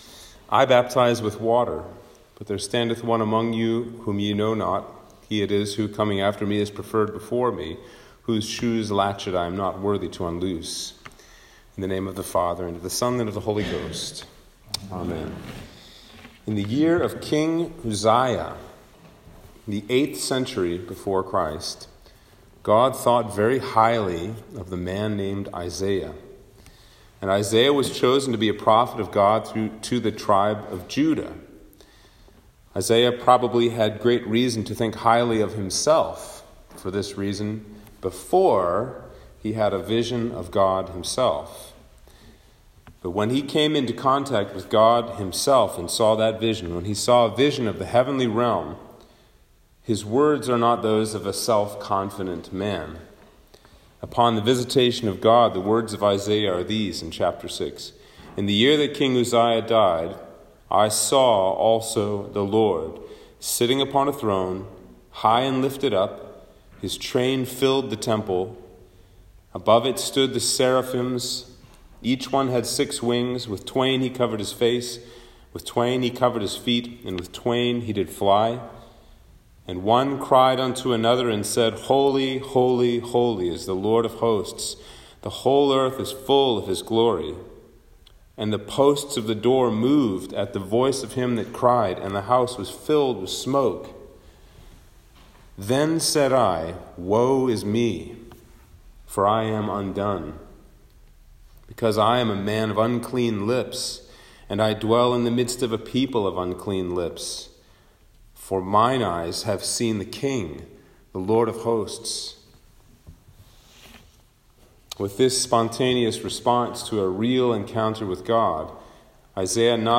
Sermon for Advent 4 - 2021